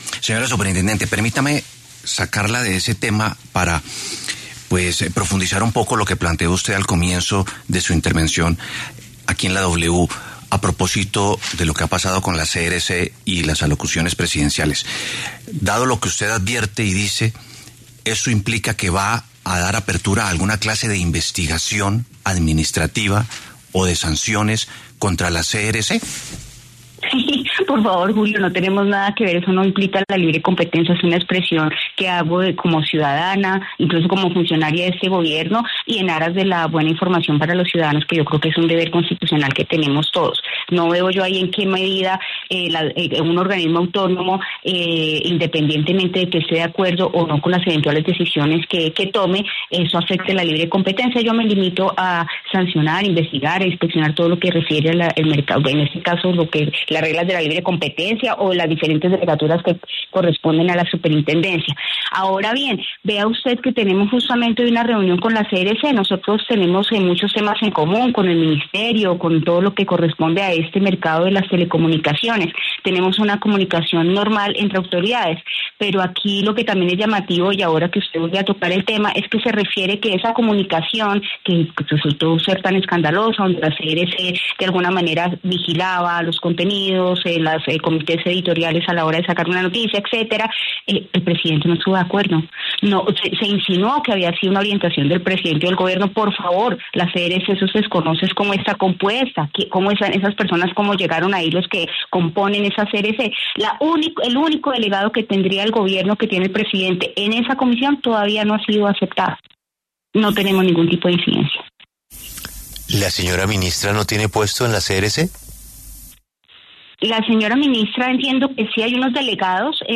La superintendente de Industria y Comercio, Cielo Rusinque, se pronunció en La W sobre el rechazo a la solicitud del mandatario para hacer una alocución presidencial este miércoles.